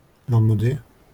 Lanmodez (French pronunciation: [lɑ̃mɔde]